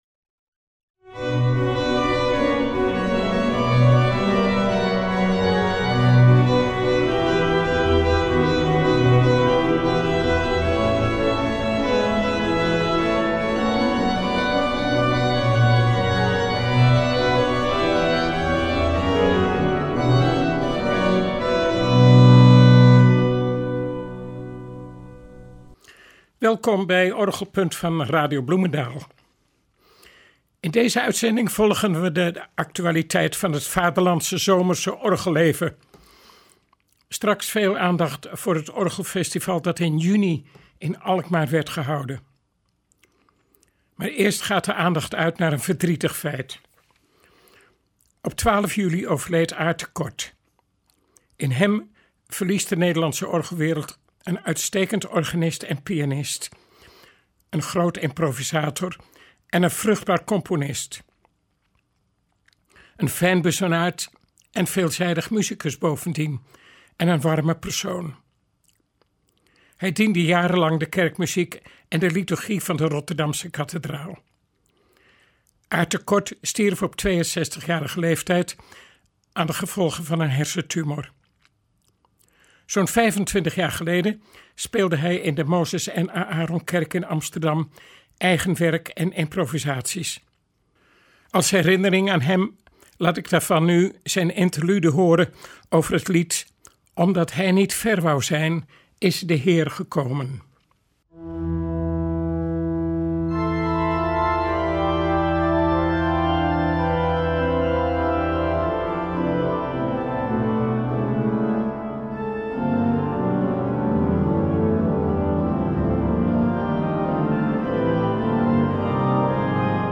In de uitzending kunnen we luisteren naar momenten uit elk van de drie rondes.